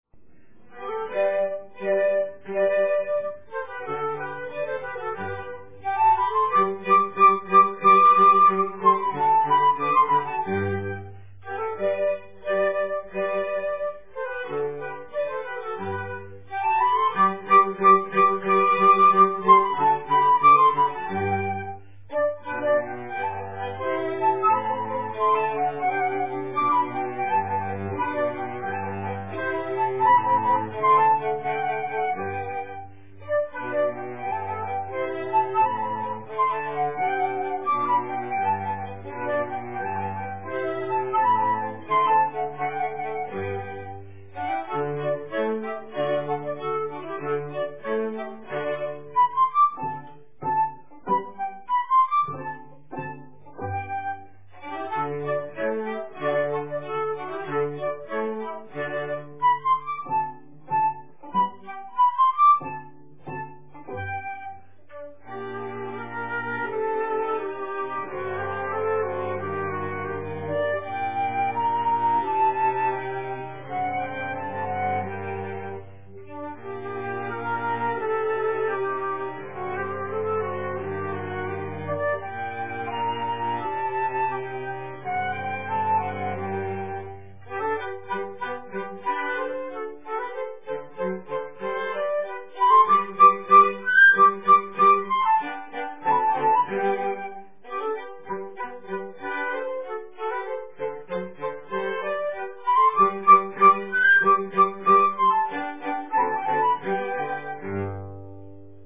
Country Dance #5